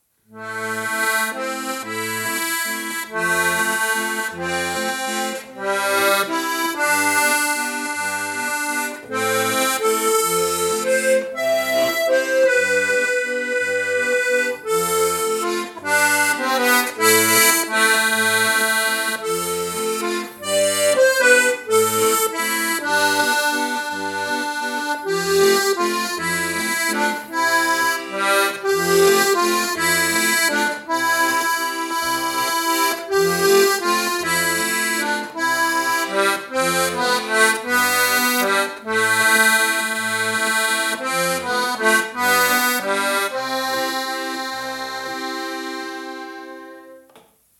GC Akkorde.mp3